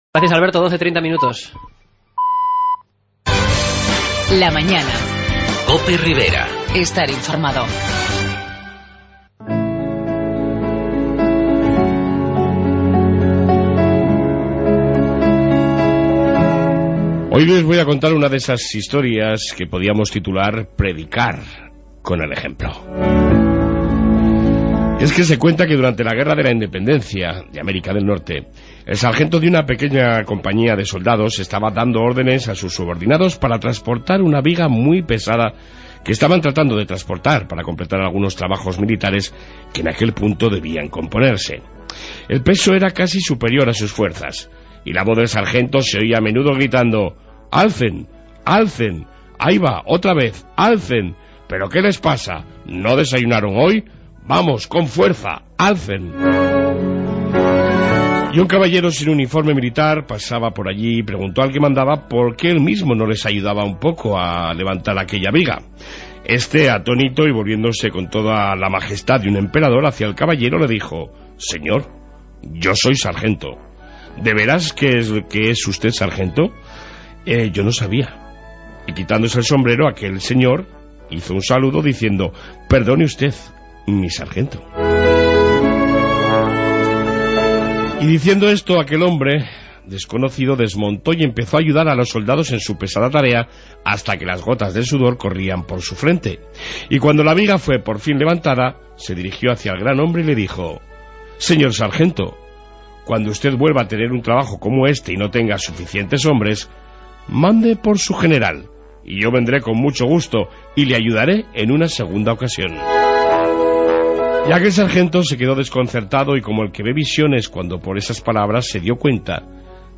Informe policía Municipal
Entrevista